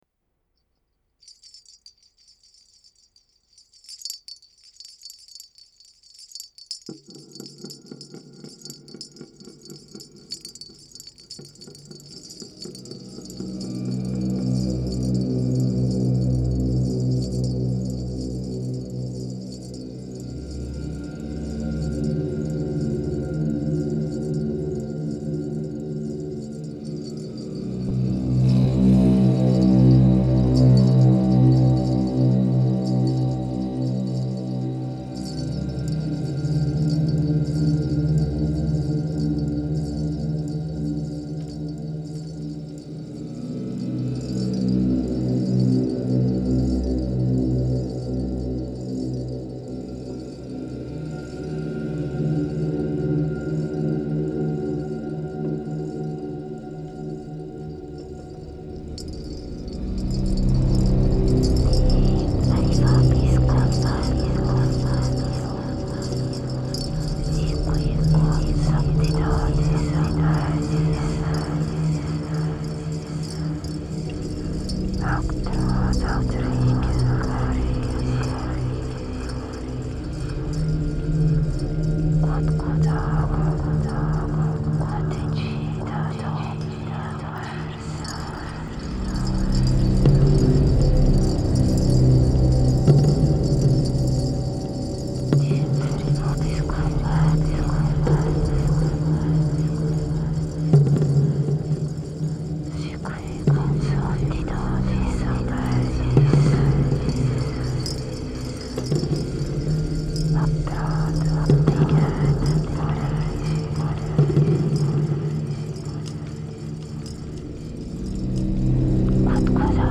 Genre: Dark Ambient, Drone.